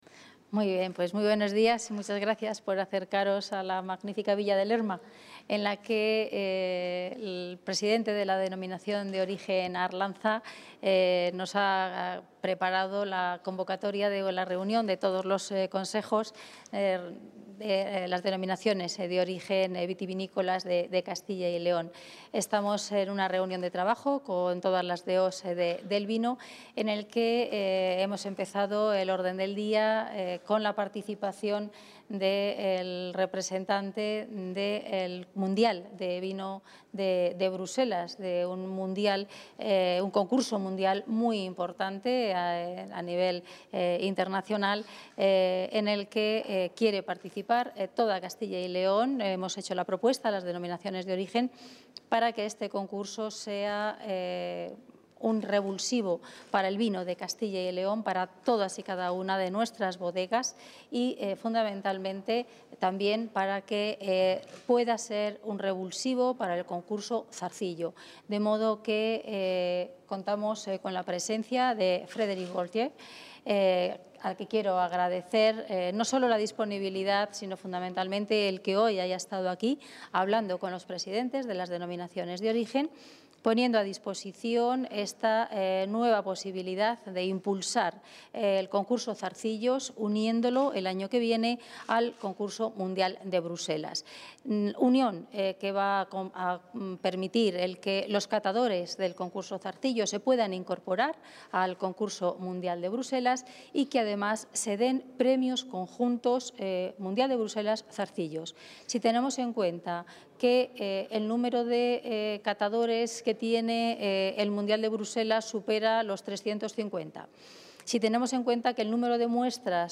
Audio consejera y portavoz.
La consejera de Agricultura y Ganadería y portavoz de la Junta de Castilla y León, Milagros Marcos, se ha reunido hoy con los presidentes de los consejos reguladores de las denominaciones de origen de vino, en Lerma, en Burgos.